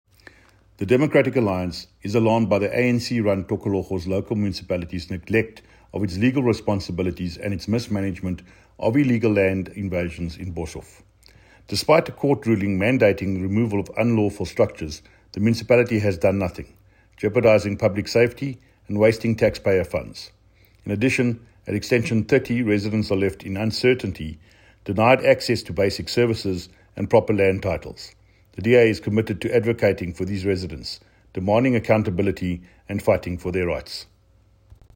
Afrikaans soundbites by David Mc Kay MPL and Sesotho soundbite by Jafta Mokoena MPL.